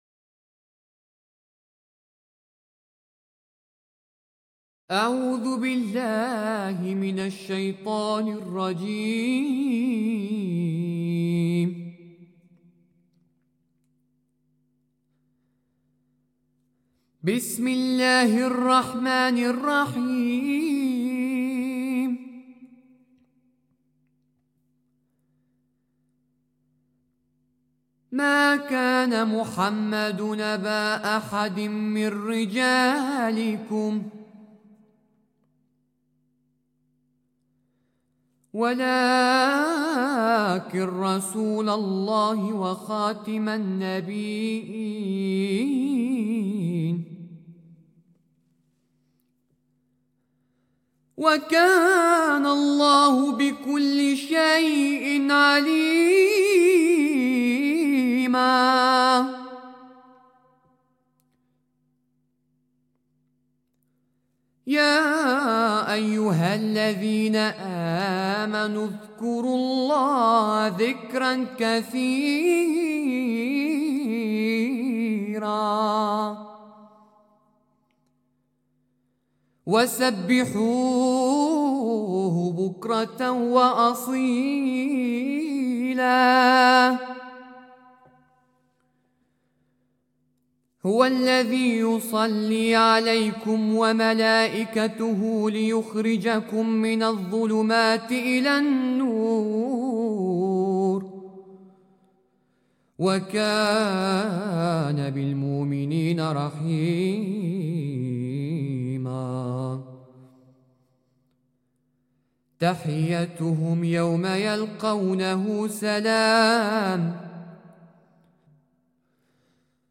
Vertolkt door ensemble Ibn Arabi, met veel pauzes.
Ik denk dat alle liederen oorspronkelijk eenstemmig zijn opgeschreven en dat ook dit lied zo een aardig beeld geeft van hoe het waarschijnlijk meestal werd uitgevoerd: eenstemmig met hooguit een simpele begeleiding.
Wat me onder meer aan de muziek aanspreekt zijn de pauzes.